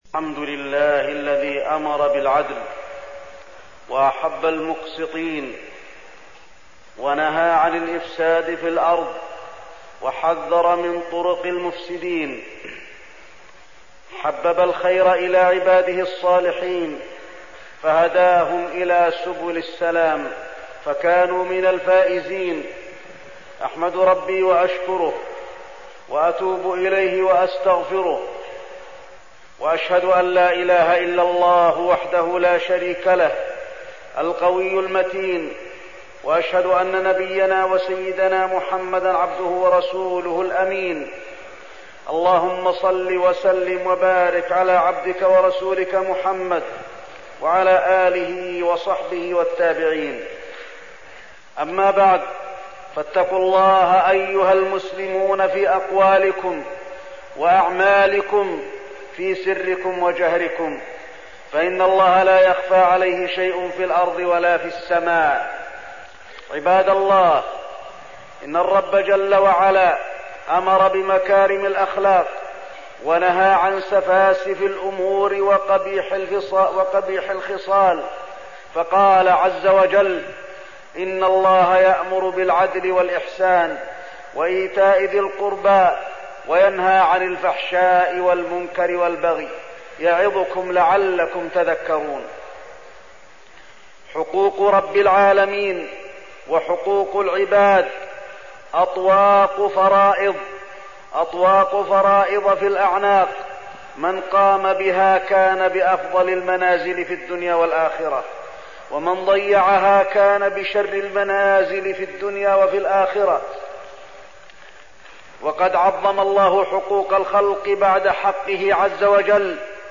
تاريخ النشر ٢٤ محرم ١٤١٨ هـ المكان: المسجد النبوي الشيخ: فضيلة الشيخ د. علي بن عبدالرحمن الحذيفي فضيلة الشيخ د. علي بن عبدالرحمن الحذيفي حقوق الله وحقوق العباد